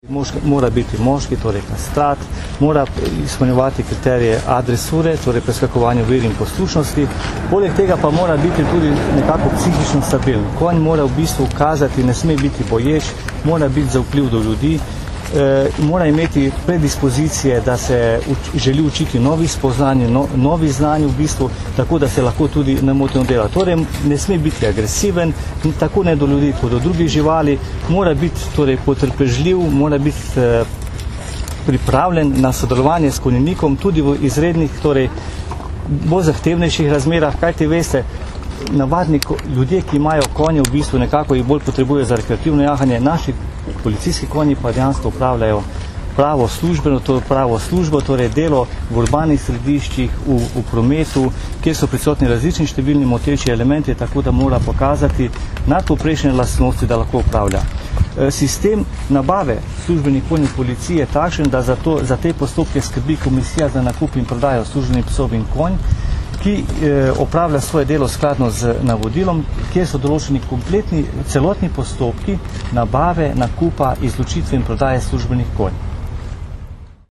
V ljubljanskih Stožicah smo novinarjem danes, 24. avgusta 2009, predstavili Postajo konjeniške policije in specifično delo policistov konjenikov, ki službene konje uporabljajo za patruljiranje, iskanje oseb, pri hujših kršitvah javnega reda in miru ter za druge naloge policije.
Zvočni posnetek izjave